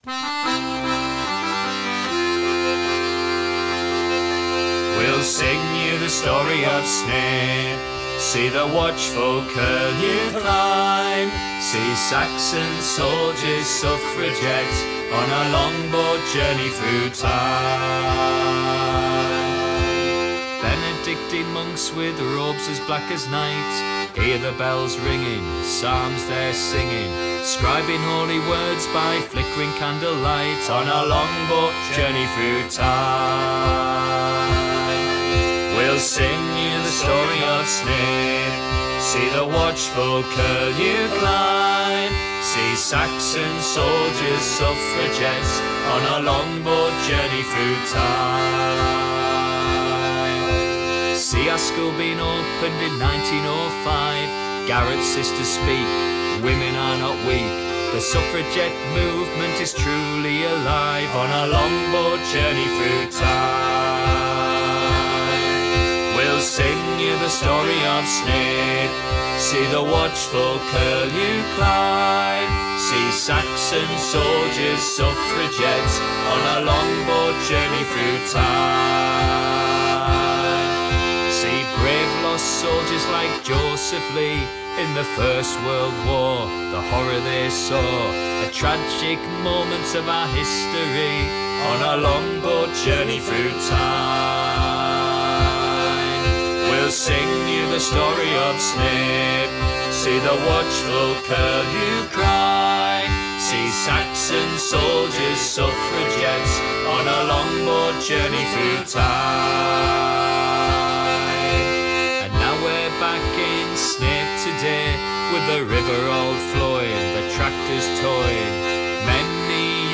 We were very lucky, last week, to be able to work with our favourite folk group, the Young'uns, via Zoom.